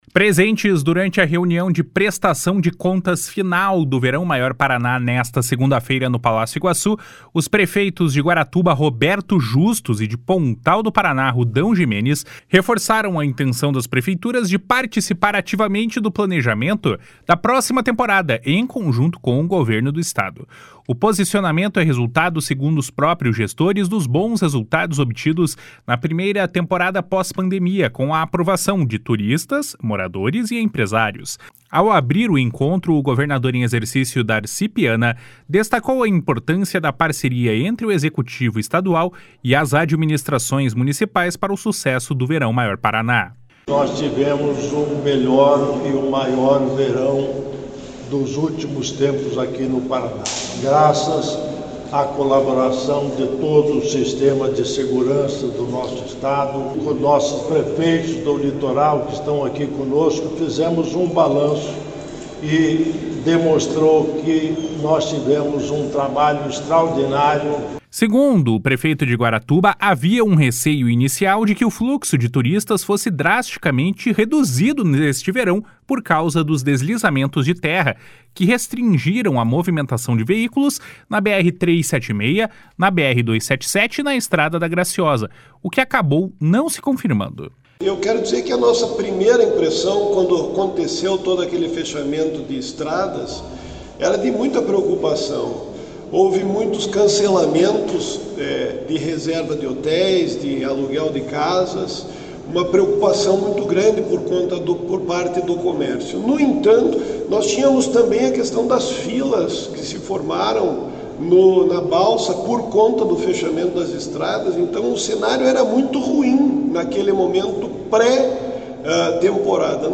// SONORA DARCI PIANA //
Segundo o prefeito de Guaratuba, havia um receio inicial de que o fluxo de turistas fosse drasticamente reduzido neste verão em função dos deslizamentos de terra que restringiram a movimentação de veículos na BR-376, BR-277 e na Estrada da Graciosa, o que acabou não se confirmando. // SONORA ROBERTO JUSTUS //
// SONORA RUDÃO GIMENES //